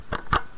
描述：按下NES按钮的声音（关闭NES）
标签： 任天堂NES 视频游戏 按键
声道立体声